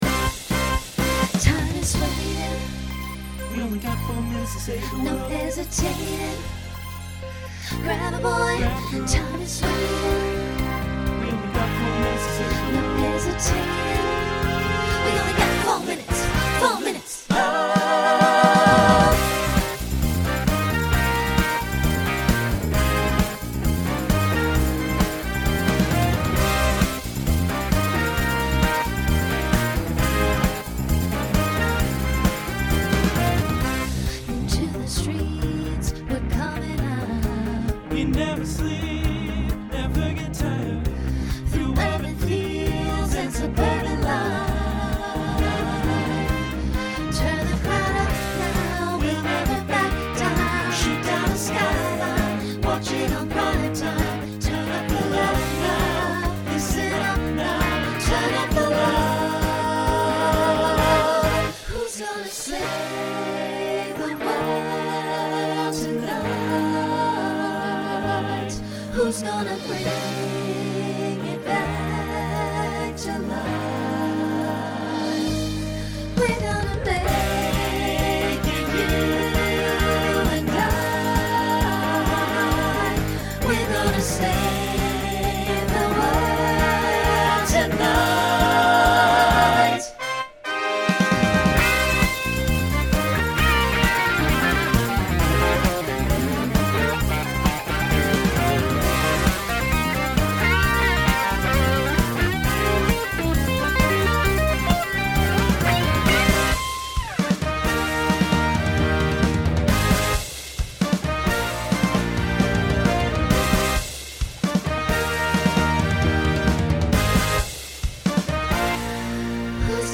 New SSA voicing for 2025.
Genre Pop/Dance
Voicing SATB , SSA